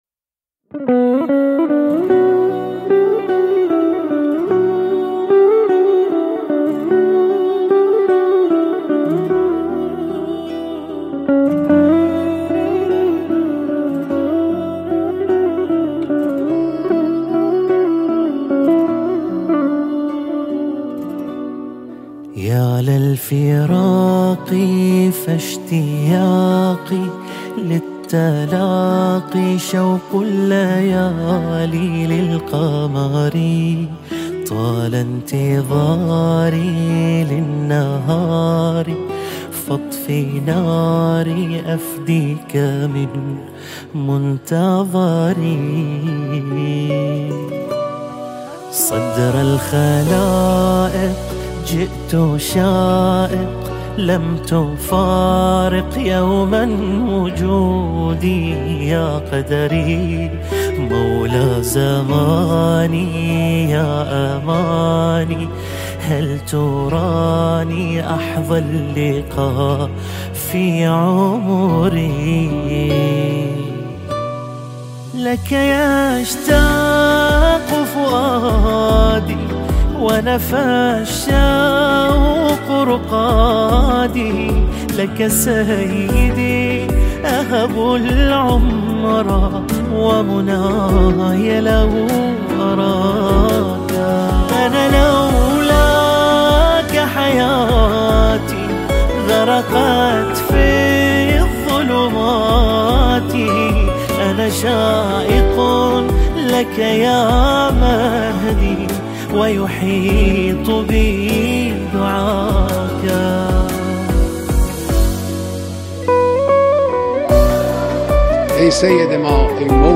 نماهنگ عربی دلنشین